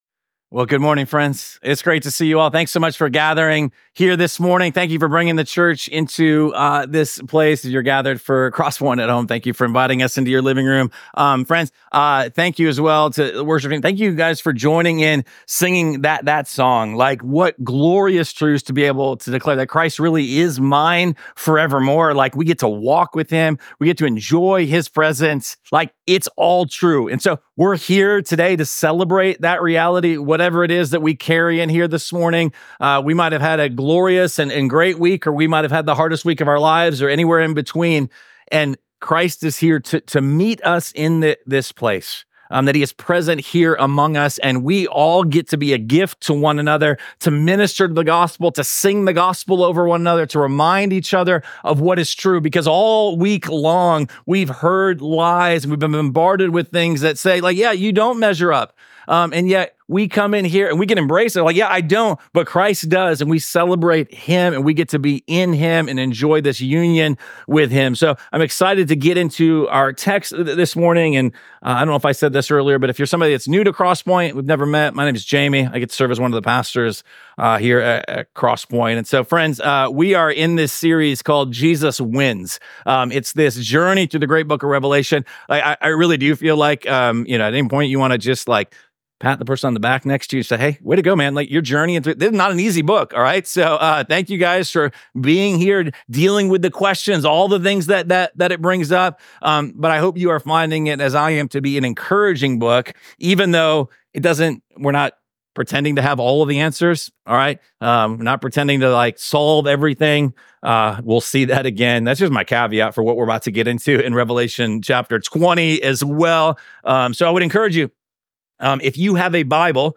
Week 15 of our series Jesus Wins: A Study of Revelation. This sermon comes from Revelation chapter 20.